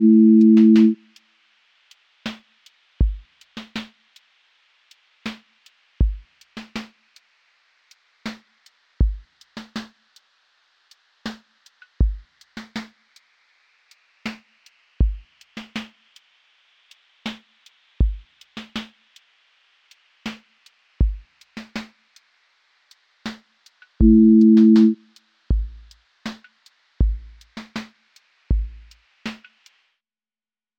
QA Listening Test lofi Template: lofi_crackle_sway
dusty lofi hip-hop study beat with rhodes haze, vinyl hiss, soft backbeat, and gentle drift
• voice_snare_boom_bap
• texture_vinyl_hiss
• motion_drift_slow